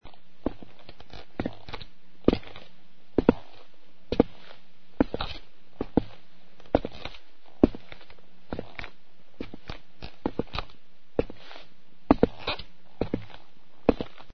PASOS DESVANPASOSDESVAN
Ambient sound effects
Pasos_desvanpasosdesvan.mp3